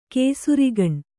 ♪ kēsurigaṇ